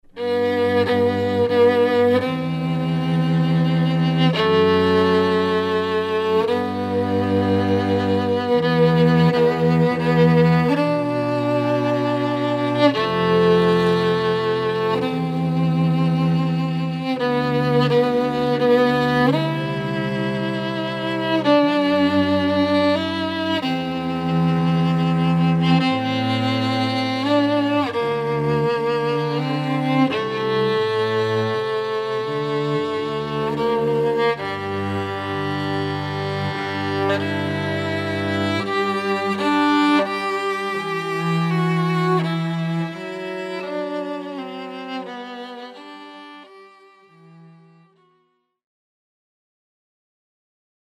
~Violin and Cello~